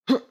player_jump_grunt.wav